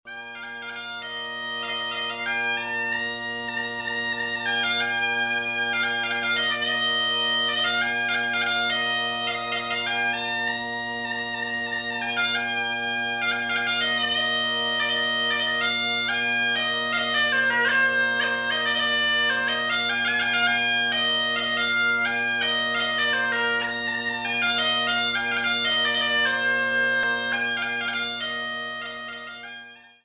Il "baghèt"